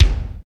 KIK XC.BDR01.wav